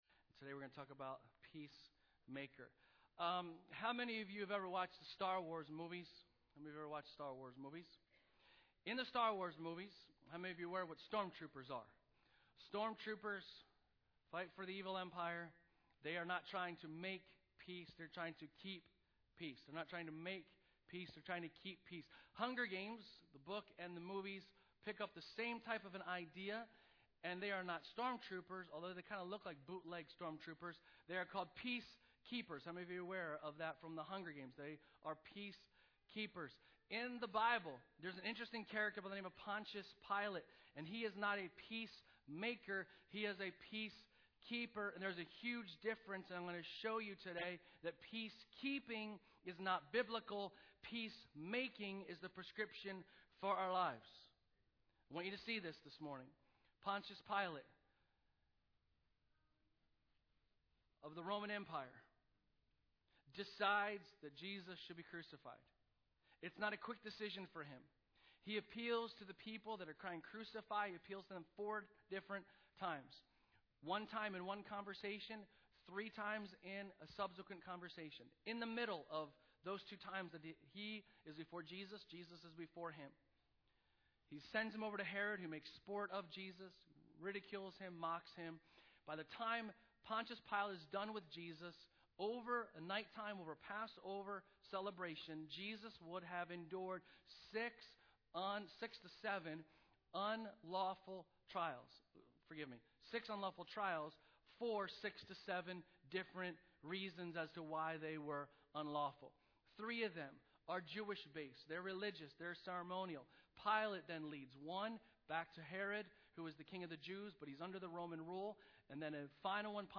Sermons | Bethany Church